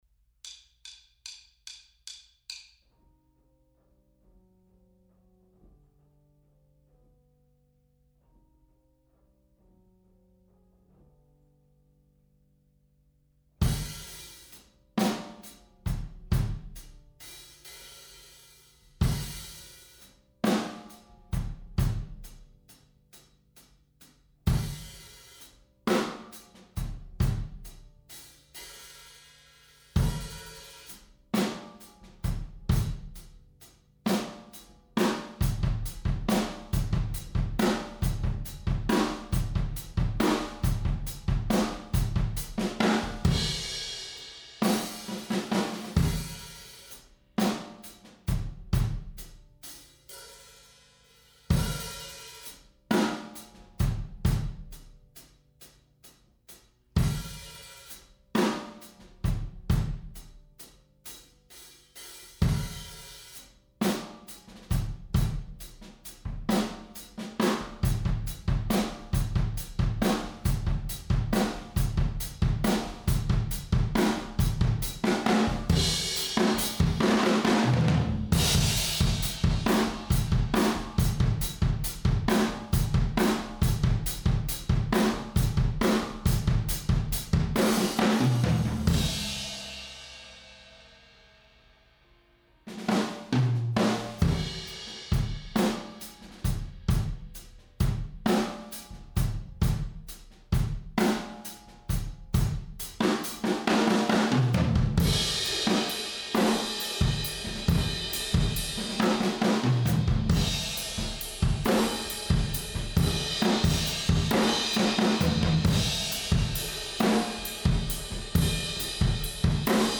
-la piste room a une grosse comp
Non tout est bien compressé ! bien bien compressé comme j'aime. Ca pompe bien
Rien n'empêche de placer une batterie dans un angle ou en travers dans une cabine.
-on a une piste room pannée différemment (kick à droite, snare à gauche entre autres)
room.mp3